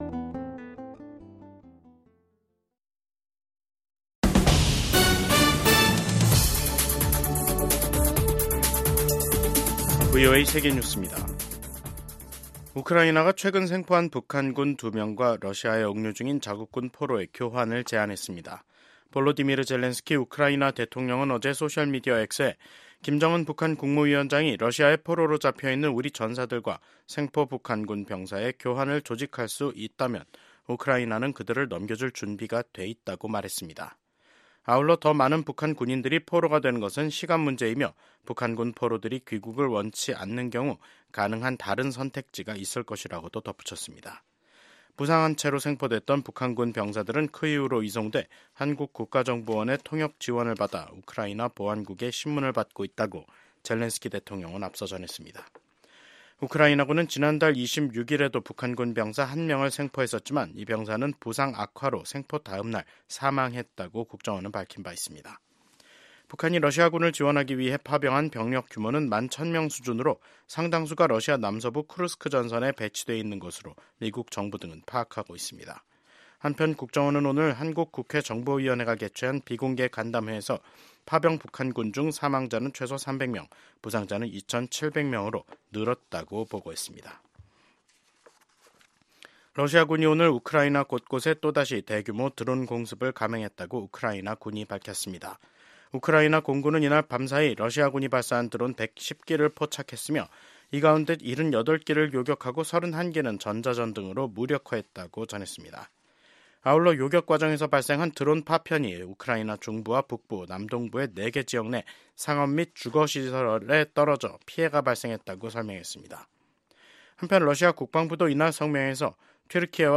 VOA 한국어 간판 뉴스 프로그램 '뉴스 투데이', 2025년 1월 13일 2부 방송입니다. 러시아 서부 쿠르스크 지역에서 20살과 26살인 북한 군인 2명이 생포됐습니다.